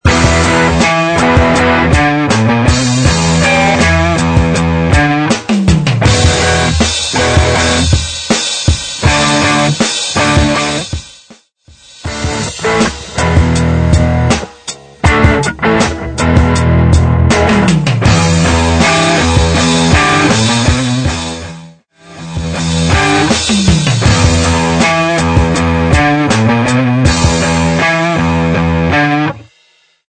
Music Beds
Rock